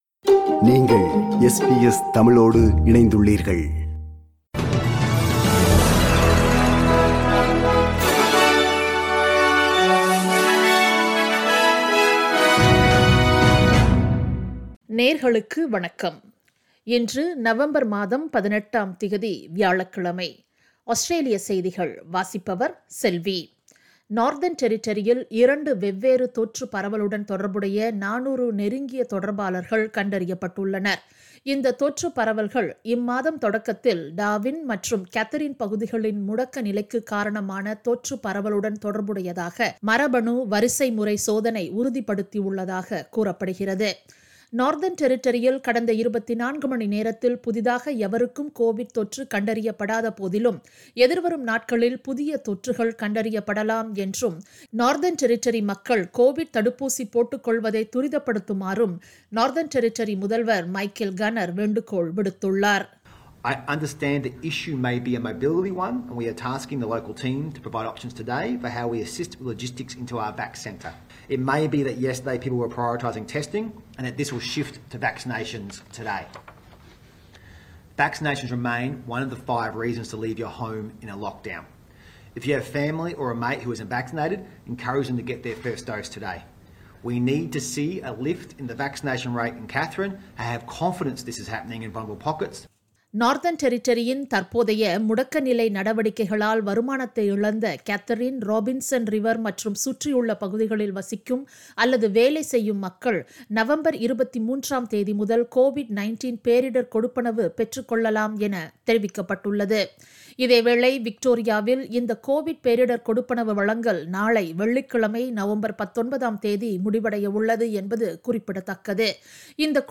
Australian news bulletin for Thursday 18 November 2021.